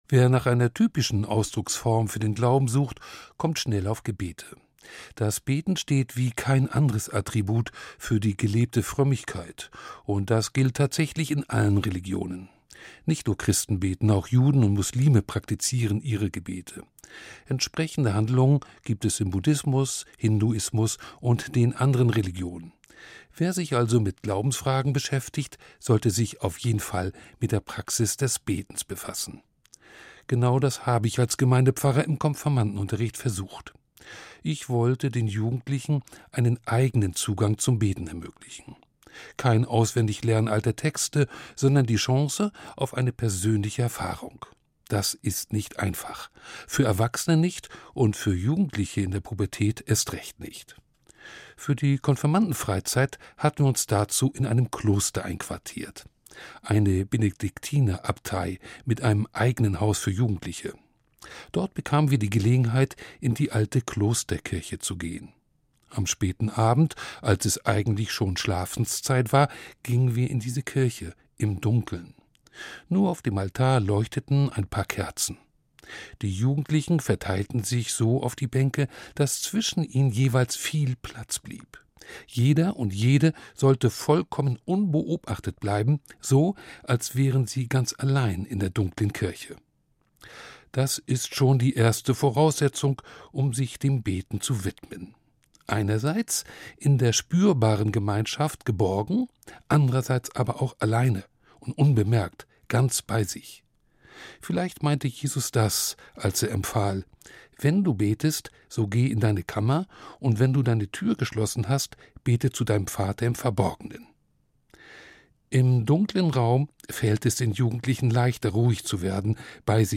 Evangelischer Pfarrer